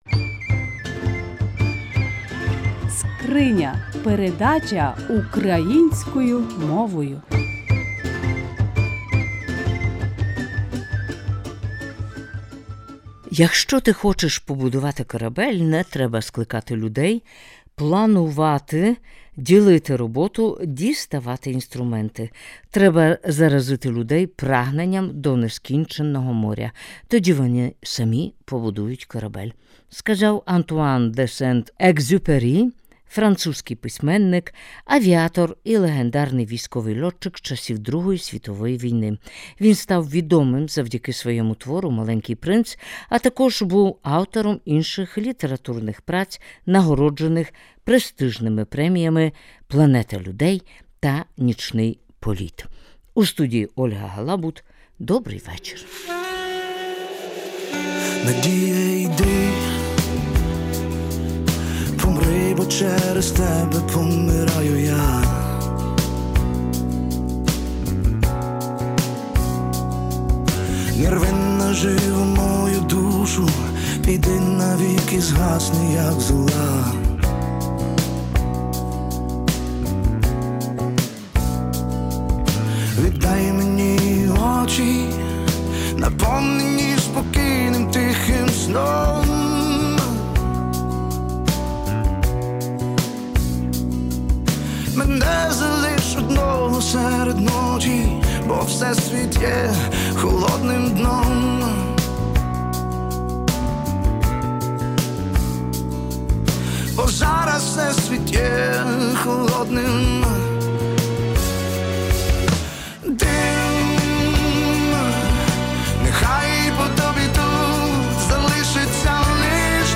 Учні перемишльської шашкевичівкм підготували поетично – літературну програму, яку пoставили в Народному домі.